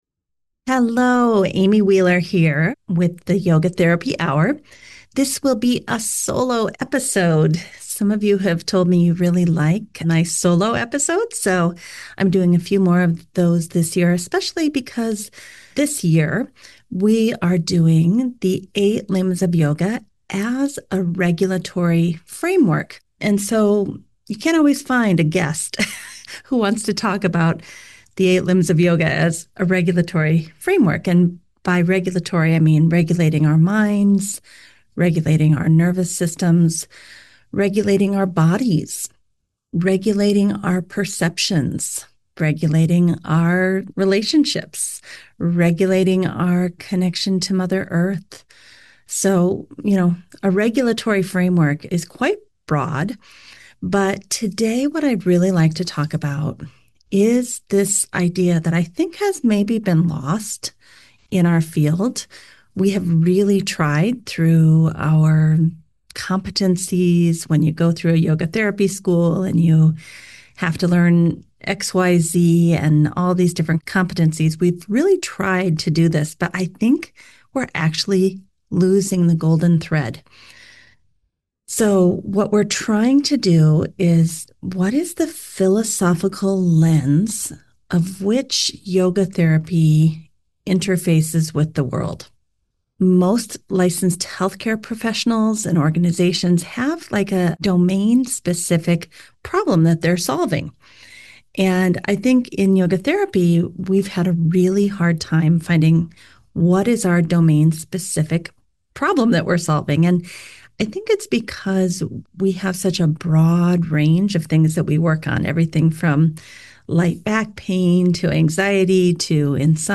In this solo conversation